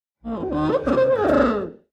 Minecraft Version Minecraft Version 1.21.5 Latest Release | Latest Snapshot 1.21.5 / assets / minecraft / sounds / mob / sniffer / happy5.ogg Compare With Compare With Latest Release | Latest Snapshot
happy5.ogg